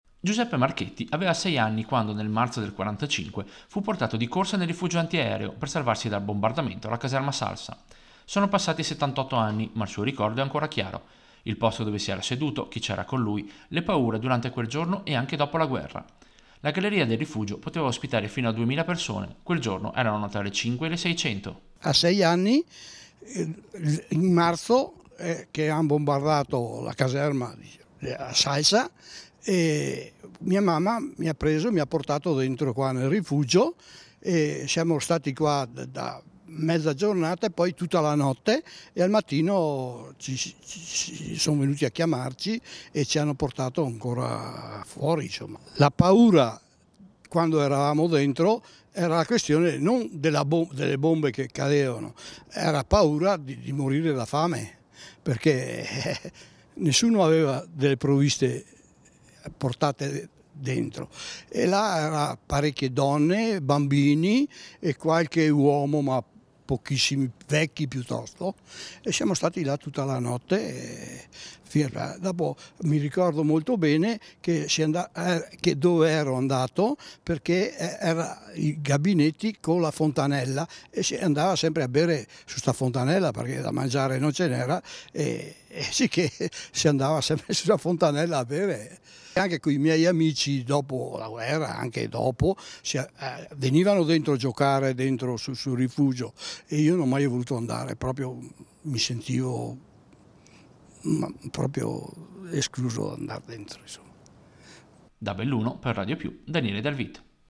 LA TESTIMONIANZA A raccontare la storia del rifugio antiaereo di Via Alzaia non ci sono solo gli studiosi, ma anche chi in quella galleria si è messo in salvo dalle bombe.